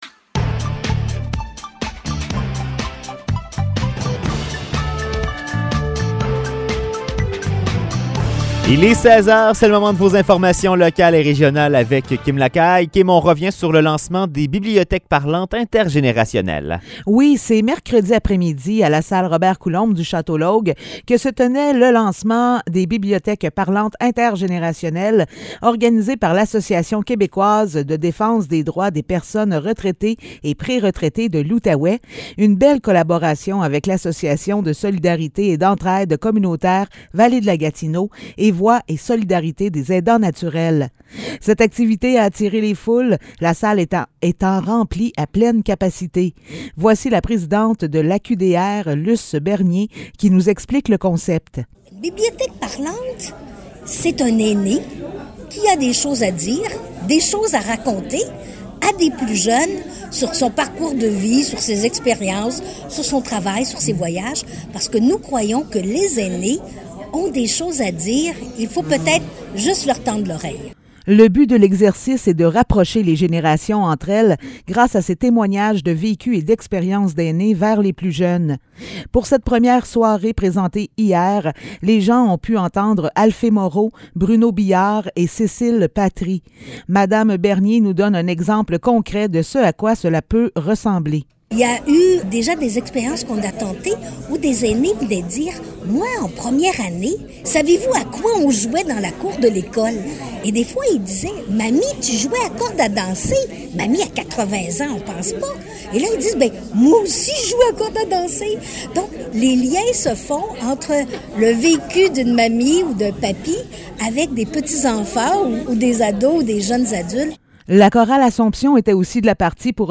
Nouvelles locales - 21 avril 2022 - 16 h